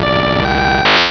Cri d'Artikodin dans Pokémon Rubis et Saphir.